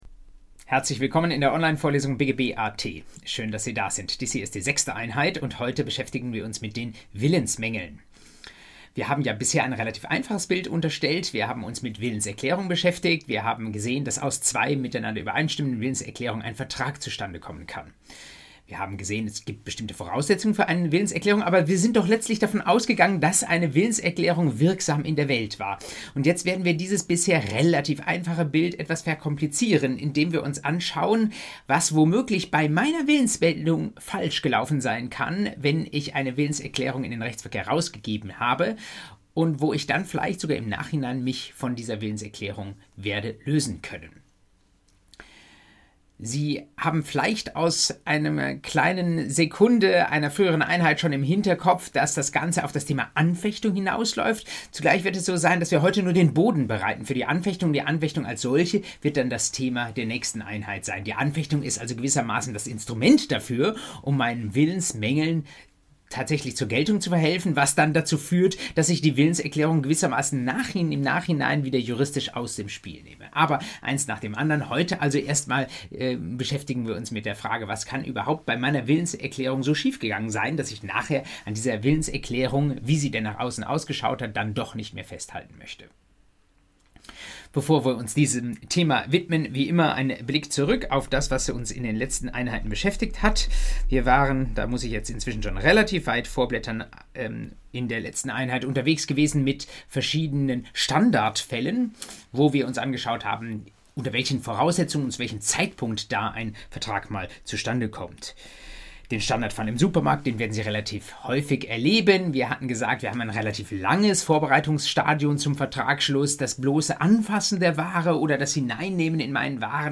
BGB AT Folge 6: Willensmängel ~ Vorlesung BGB AT Podcast